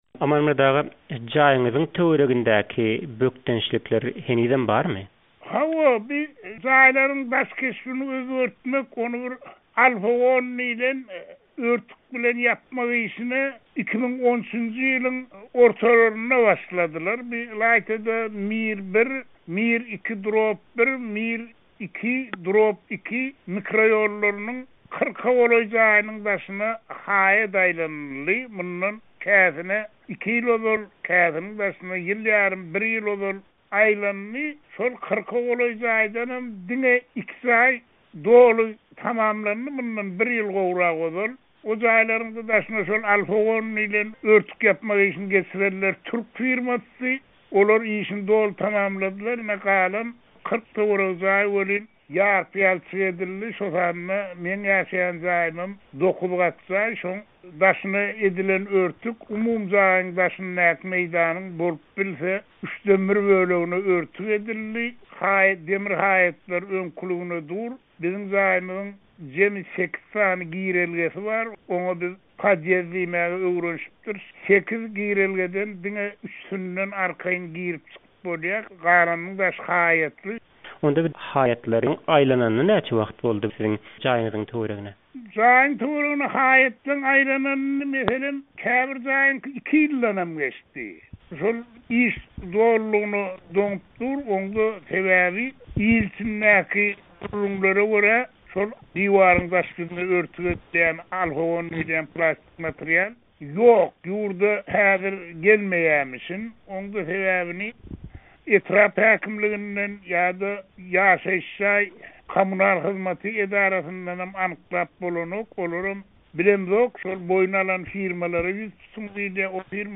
söhbetdeş boldy